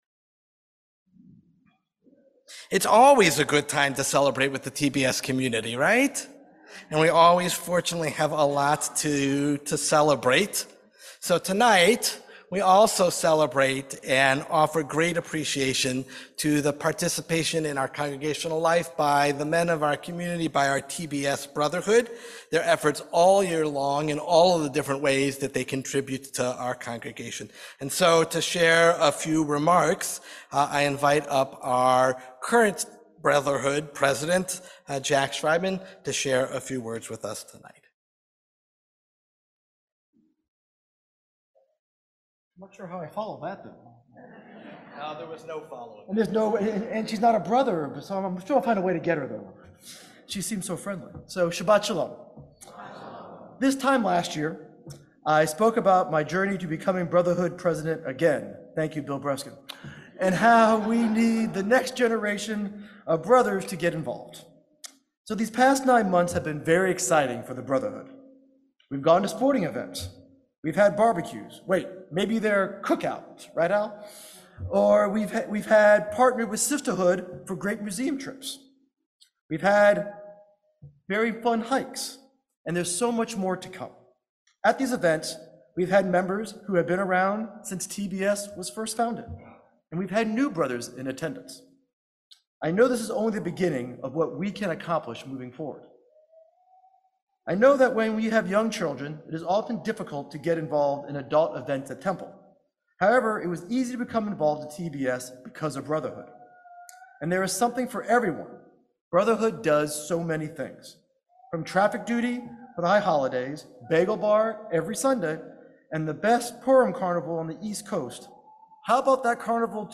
Brotherhood Shabbat Service March 29, 2024